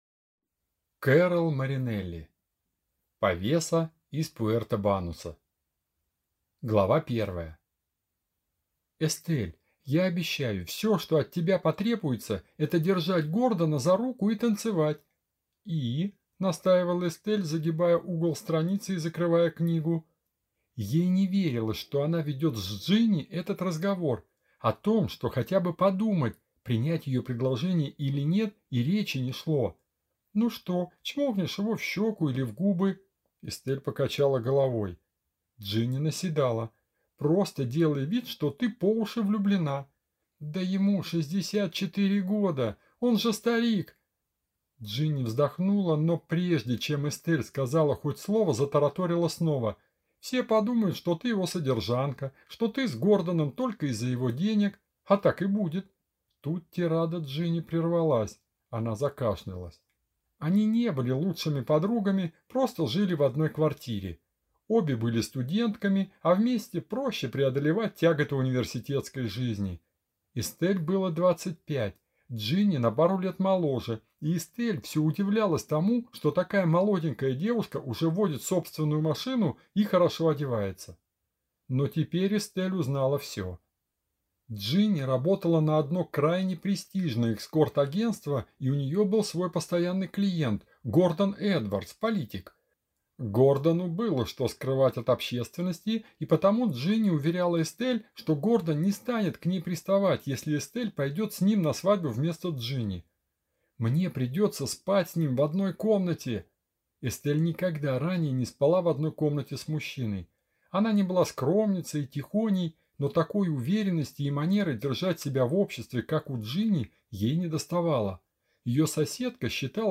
Аудиокнига Повеса из Пуэрто-Бануса | Библиотека аудиокниг